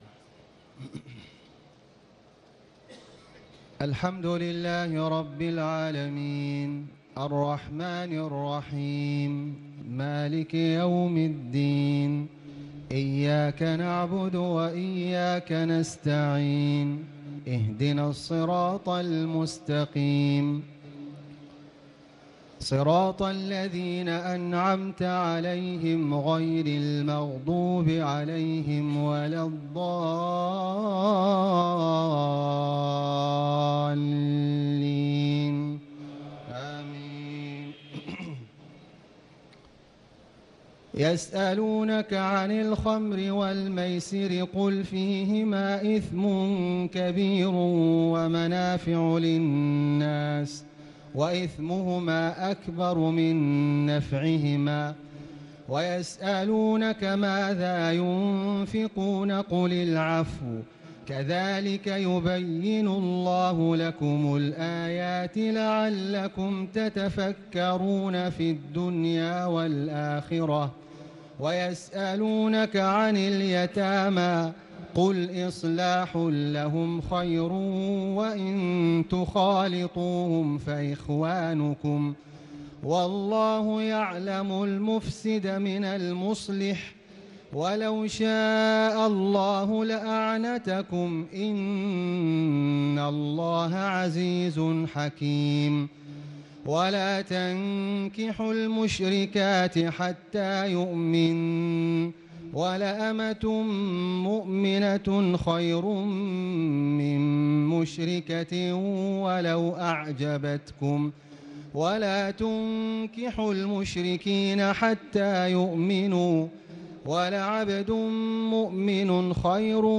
تهجد ليلة 22 رمضان 1438هـ من سورة البقرة (219-252) Tahajjud 22 st night Ramadan 1438H from Surah Al-Baqara > تراويح الحرم المكي عام 1438 🕋 > التراويح - تلاوات الحرمين